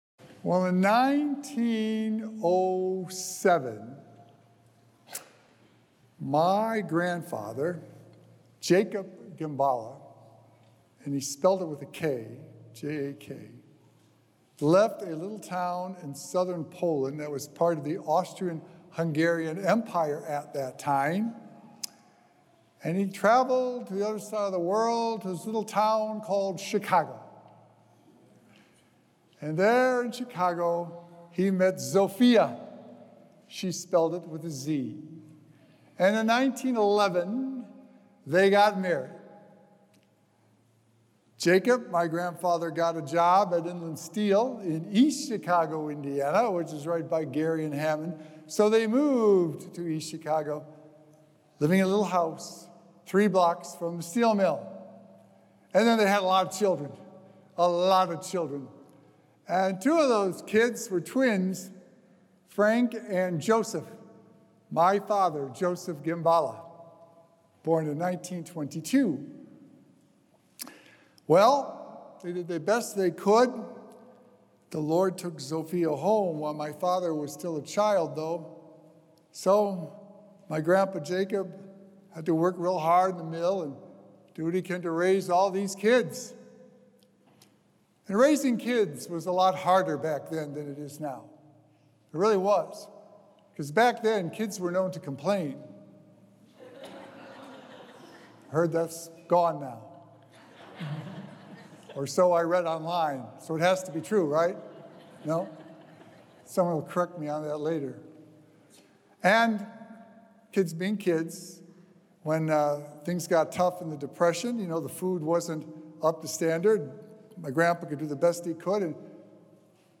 Sacred Echoes - Weekly Homilies Revealed
Recorded Live on Sunday, July 6th, 2025 at St. Malachy Catholic Church.